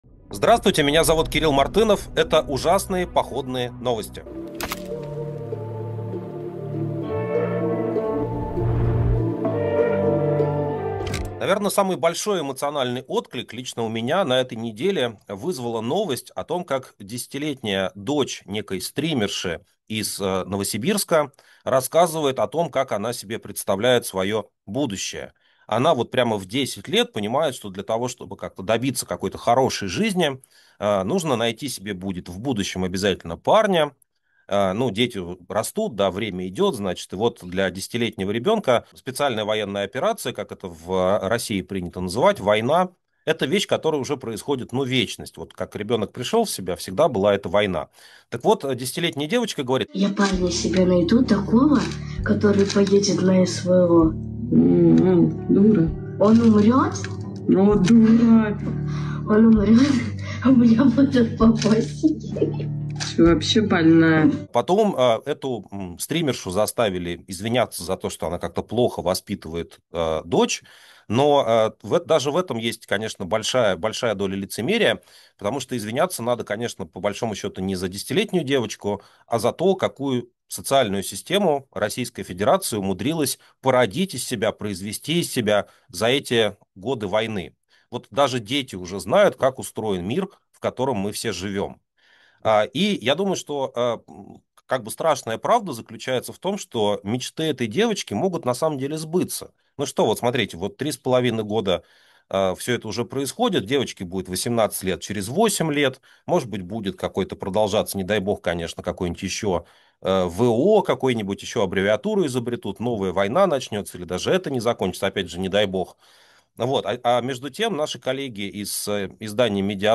Эфир ведёт Кирилл Мартынов